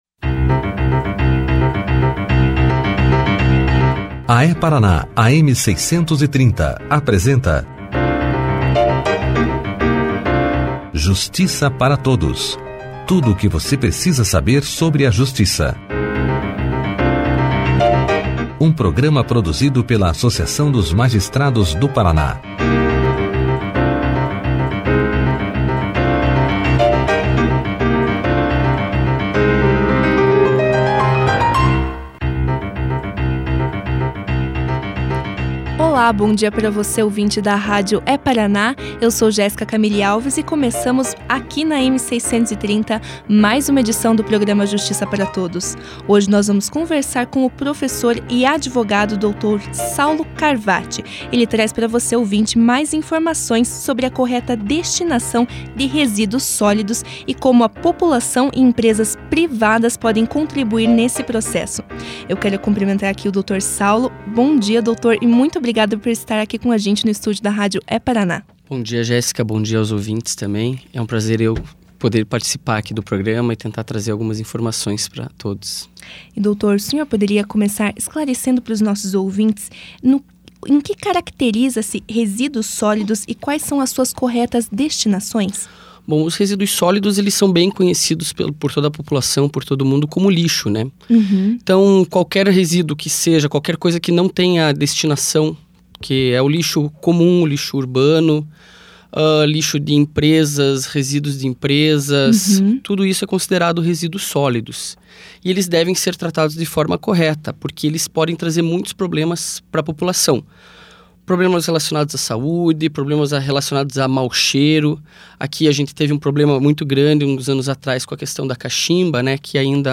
rádio É-Paraná
entrevista